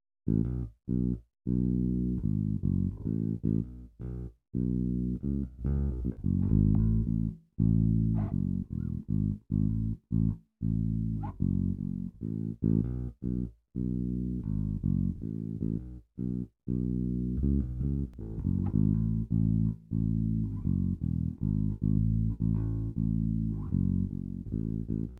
The primary part/riff of the song alternates between the I and the II chord.
bass-riff.mp3